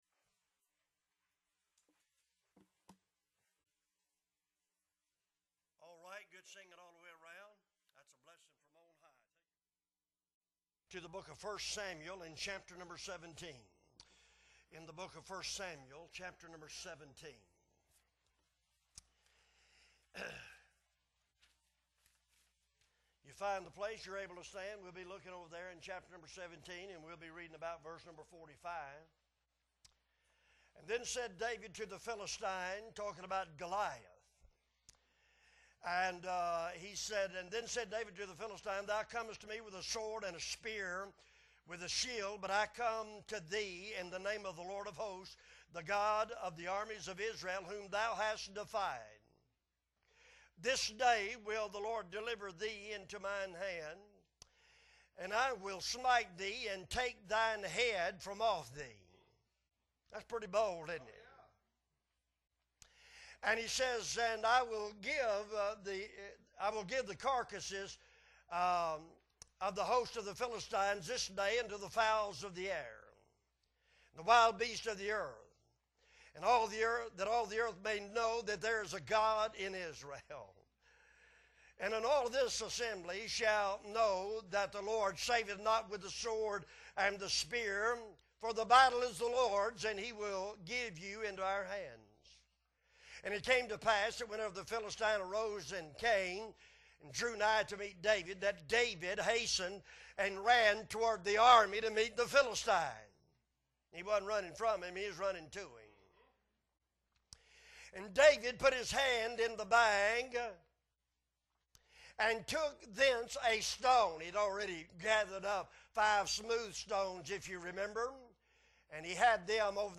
December 4, 2022 Sunday Morning Service - Appleby Baptist Church